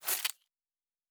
Sci-Fi Sounds / Weapons / Weapon 13 Foley 2.wav
Weapon 13 Foley 2.wav